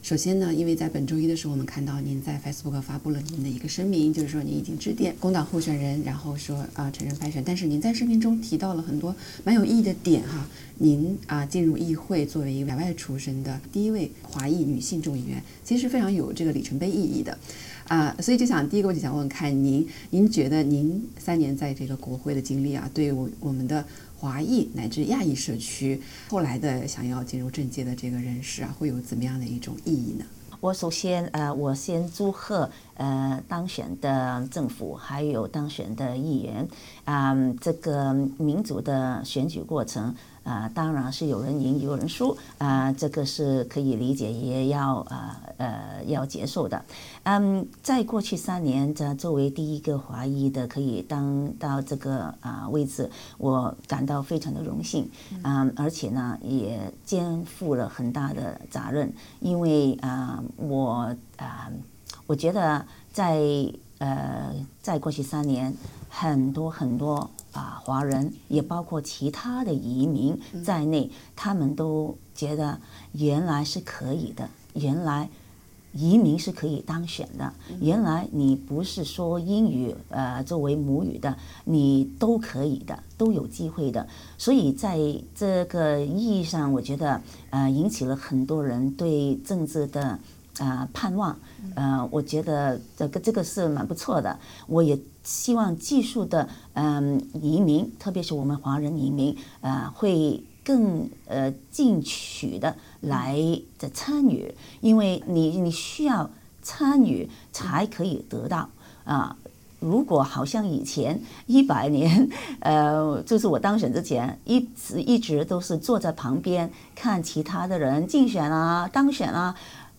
而大选中一些华裔澳大利亚人认为廖婵娥不使用微信传达竞选信息，似乎是在刻意与华人社区拉开距离，她又是如何回应的？（点击上方图片收听完整采访）